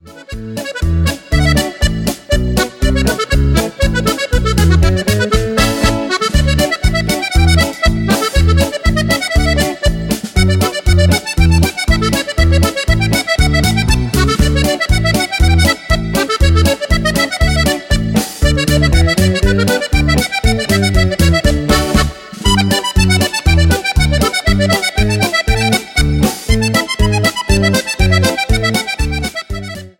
POLKA  (02.00)